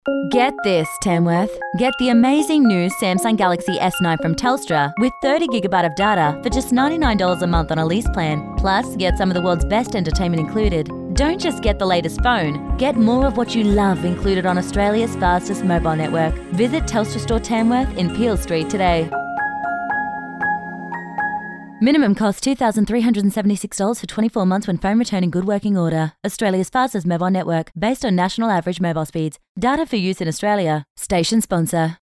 Female
English (North American), English (Australian)
Teenager (13-17), Yng Adult (18-29)
Radio Commercials